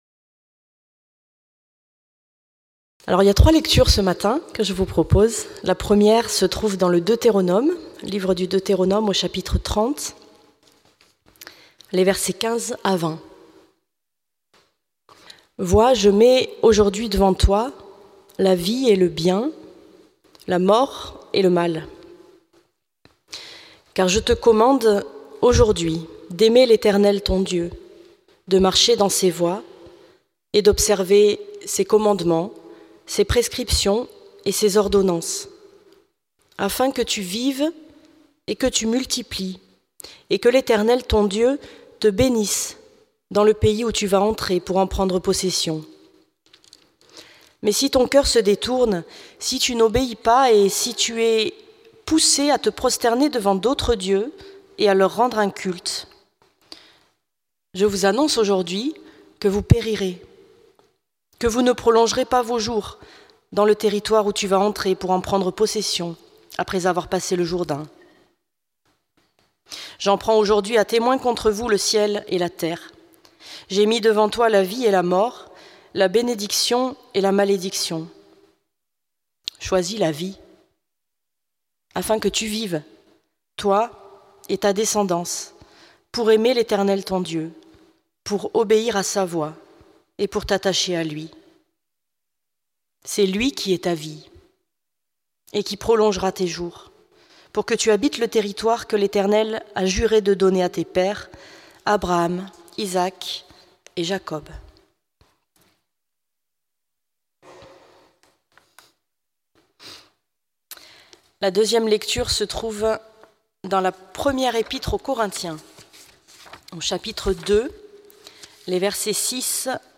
Prédication du 15 février 2026.